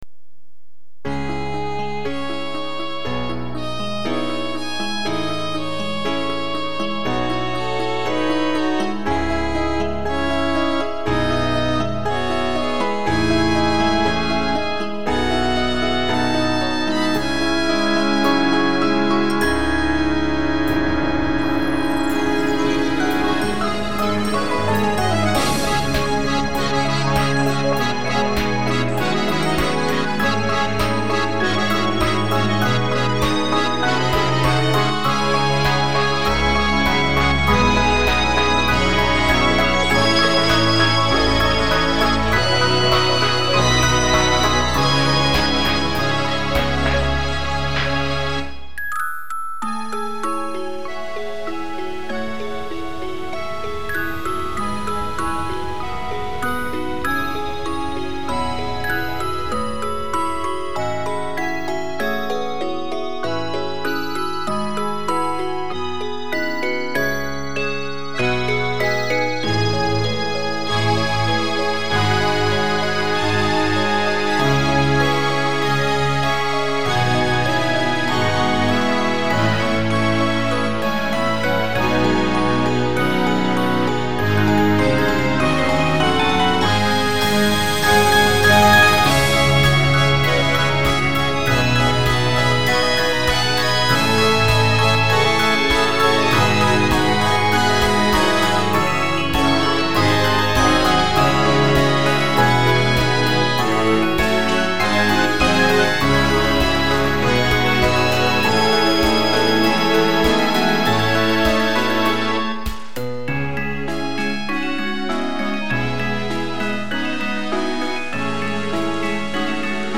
一部同時発音が10パート越え。